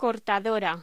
Locución: Cortadora
voz